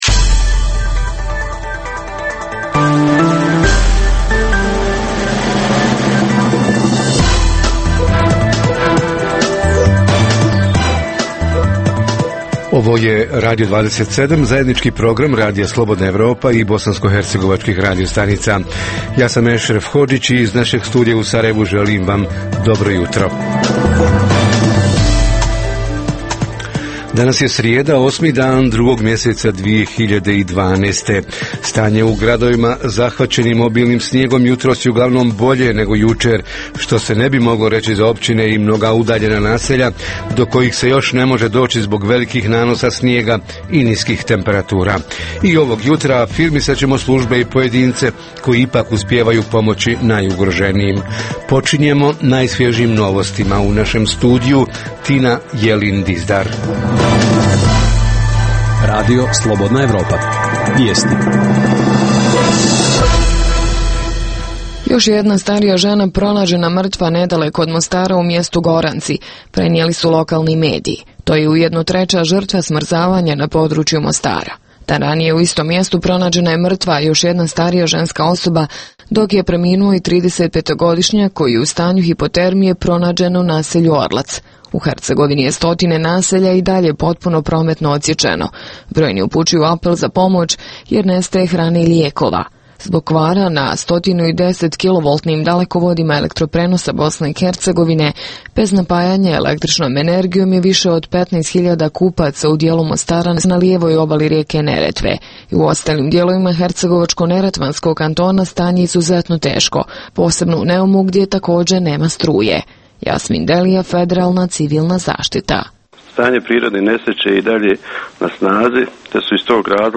Civilna zaštita u uvjetima elementarne nepogode – prirodne nesreće – šta pokazuje iskustvo iz još prisutnih nedaća izazvanih obilnim snijegom? Reporteri iz cijele BiH javljaju o najaktuelnijim događajima u njihovim sredinama.